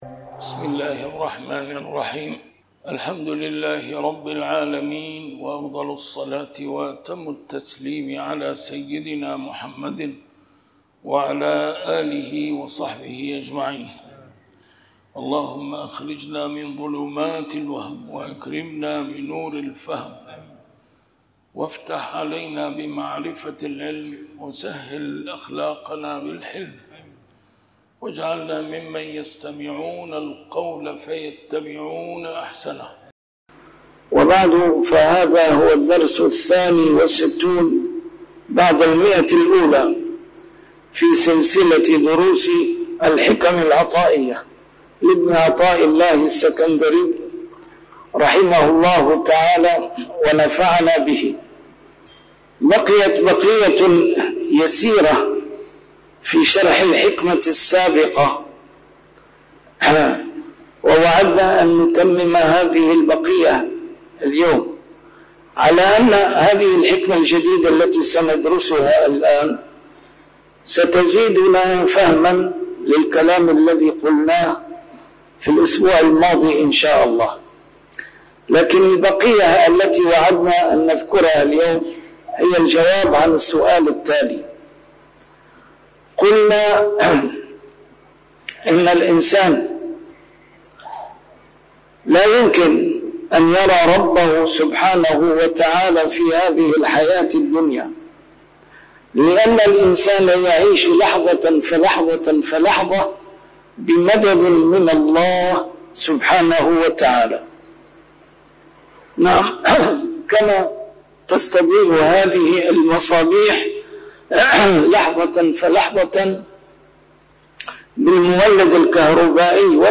A MARTYR SCHOLAR: IMAM MUHAMMAD SAEED RAMADAN AL-BOUTI - الدروس العلمية - شرح الحكم العطائية - الدرس رقم 162 شرح الحكمة 138+139